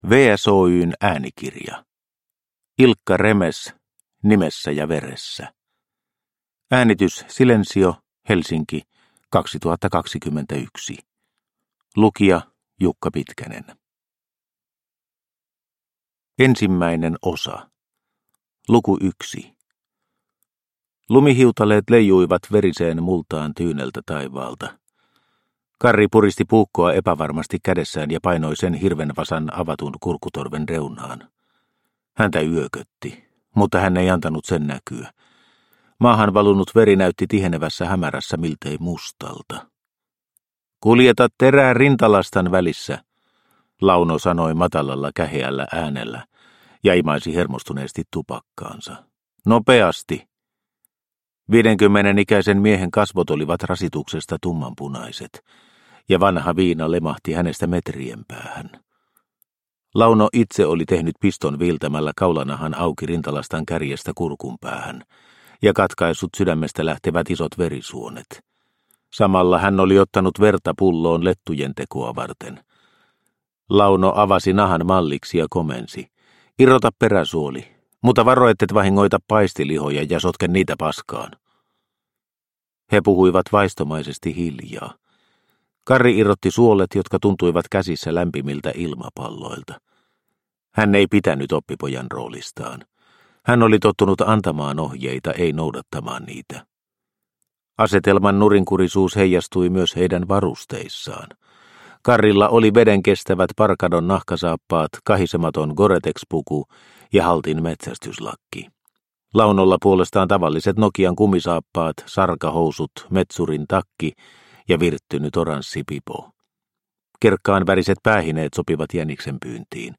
Nimessä ja veressä – Ljudbok – Laddas ner